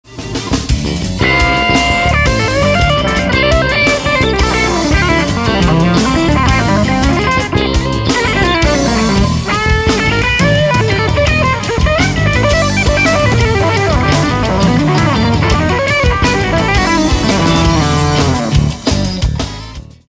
Keep in mind these are close miced with an SM57 and recorded on a home PC. No room tone at all....
Glaswerks SOD50 just recorded w/no HRM, compare to Fuchs